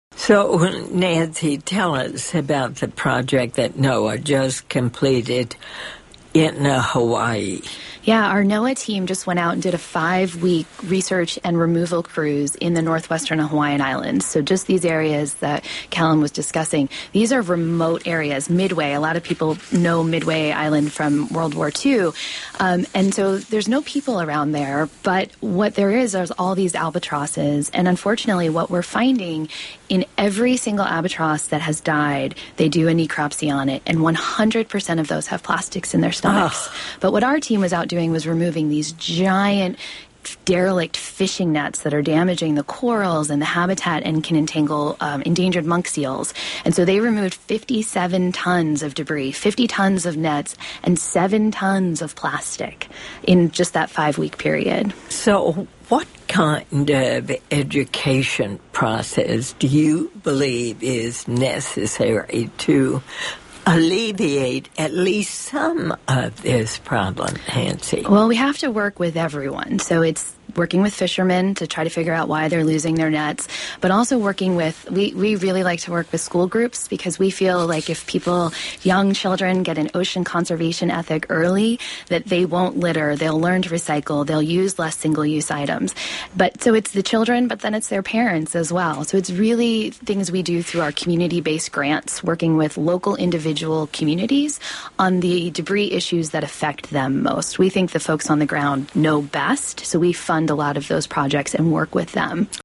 November 5, 2014 (Saving Seafood) — On yesterday’s broadcast of NPR’s “The Diane Rehm Show,” a panel of environmental experts assembled to discuss the effects of discarded waste on the world’s oceans.